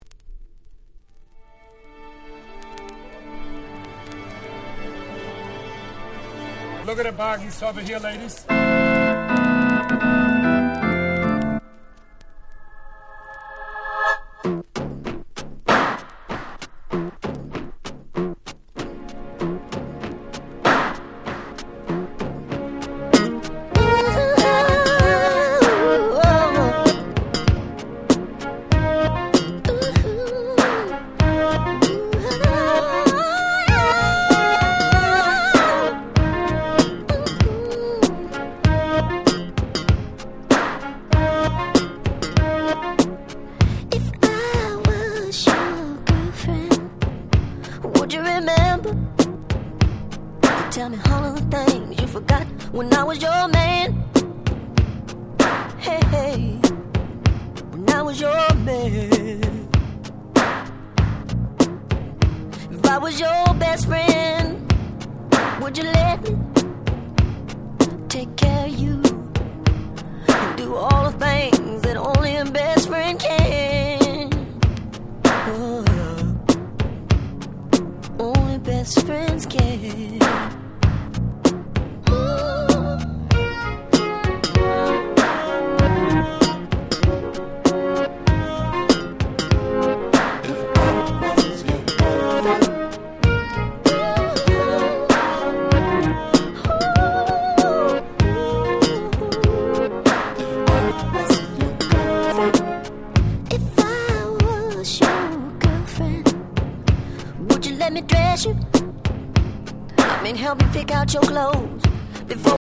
87' Super Hit R&B !!
彼の魅力あふれる80'sナンバー。